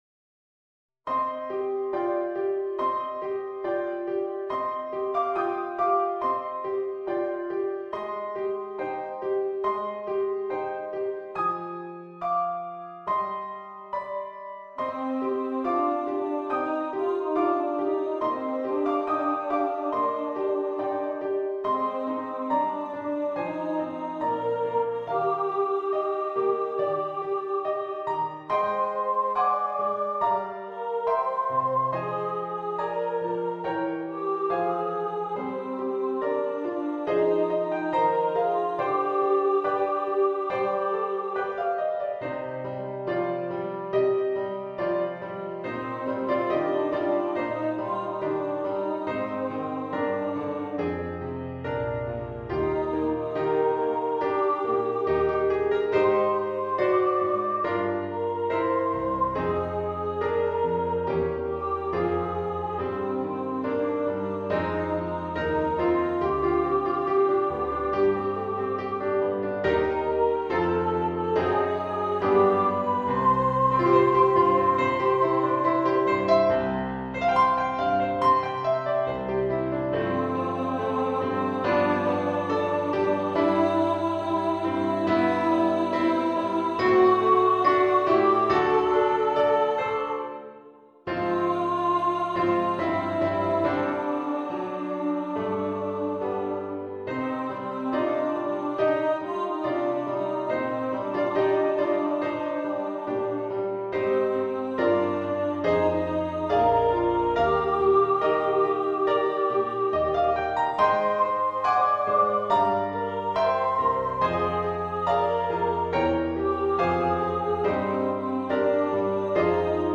Christmas Children Soprano | Ipswich Hospital Community Choir